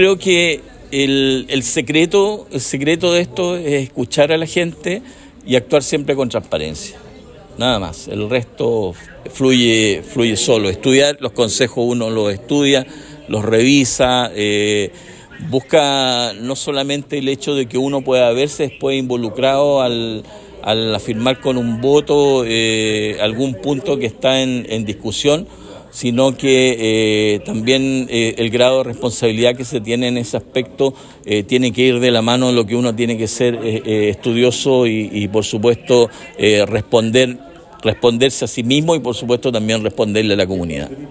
Este martes, Jorge Castilla asistió por última vez al Concejo Municipal de Osorno en su rol de concejal, luego de que anunciara su renuncia para iniciar un nuevo desafío en su carrera política: postular a un cargo parlamentario en las elecciones de 2025.